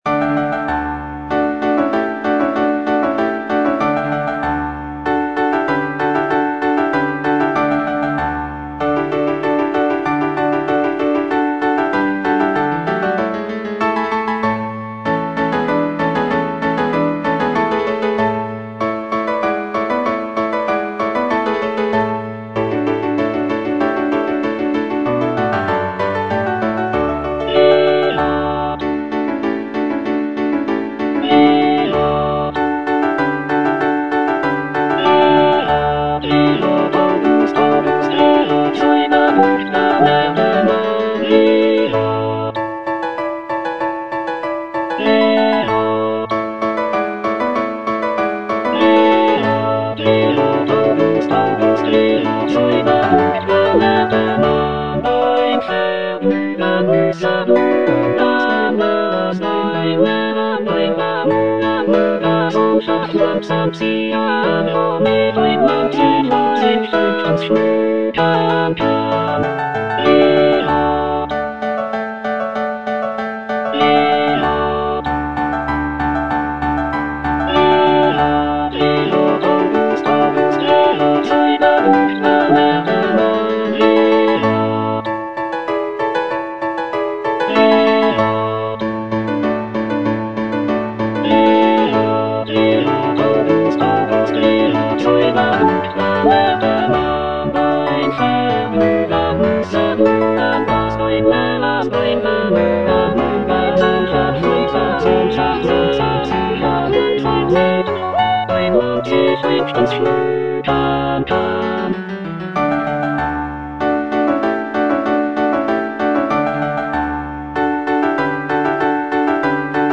Cantata